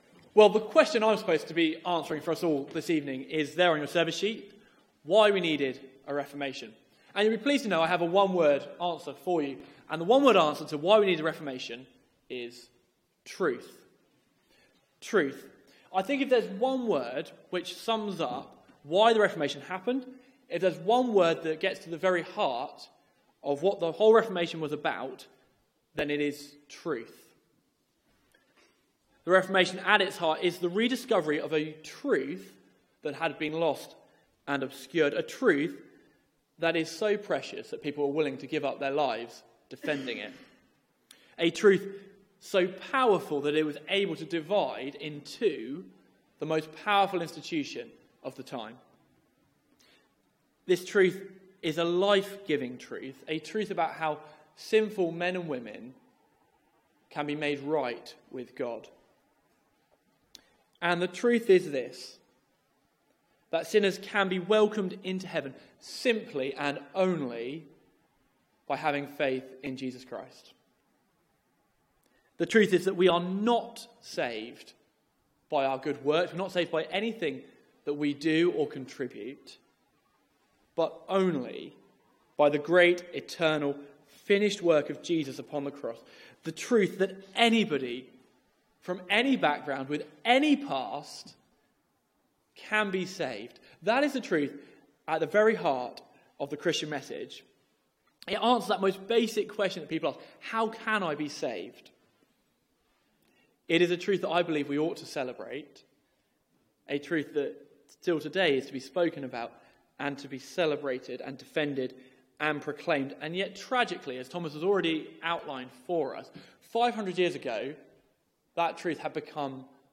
Media for 6:30pm Service
Series: Reformation 500 Theme: The 'why how and what' of the Reformation Sermon